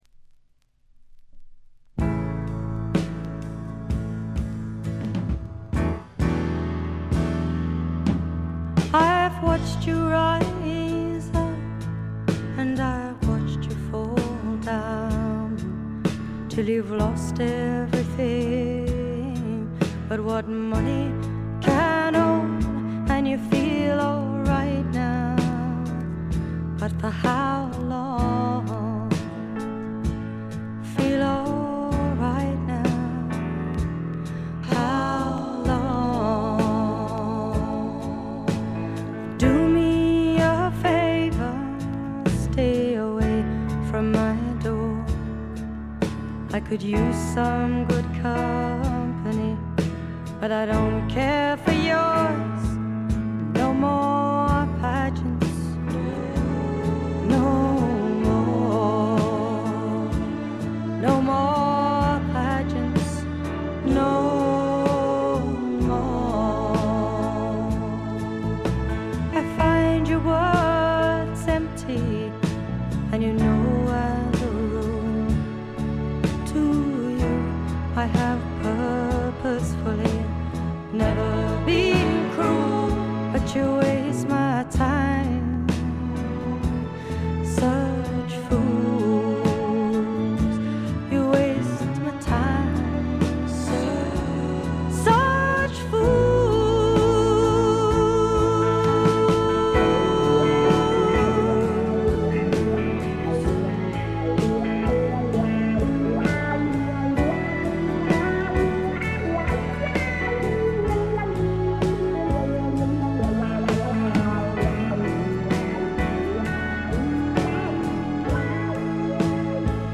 ところどころでチリプチがやや目立ちますが凶悪なノイズはありません。
1stのようないかにもな英国フォークらしさは影を潜め、オールドタイミーなアメリカンミュージック風味が加わってきています。
試聴曲は現品からの取り込み音源です。
vocals, piano, acoustic guitar